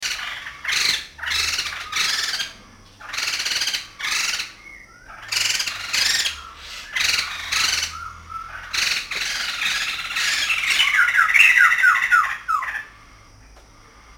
Sumatran laughinghthrush (Garrulax bicolor) -EN
Garrulax-bicolor-song.mp3